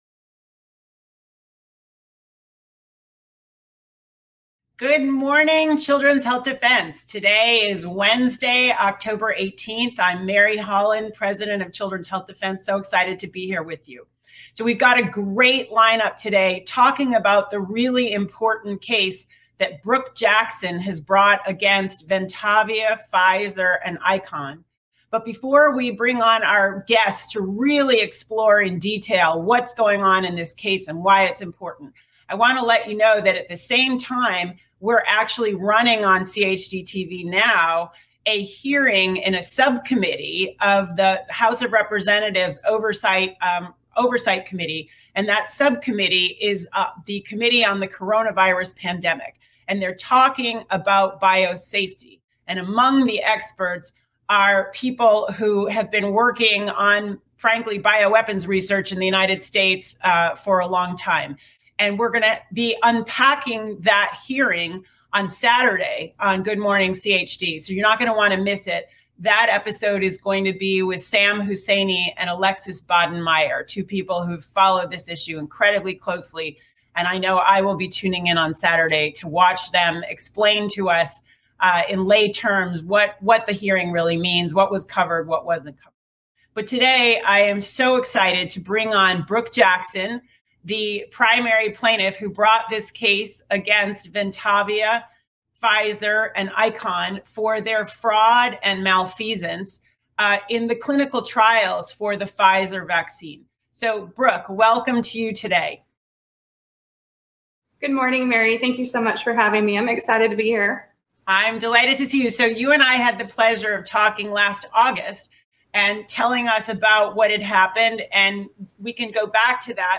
Legal Panel
Research scientists, attorneys and a clinical trial whistleblower share insights into the legal affairs and physical implications of the COVID-19 "immunization" campaign, covering the manufacturing processes and laboratory studies relating to these injections while also pointing out the observable effects of their rollout.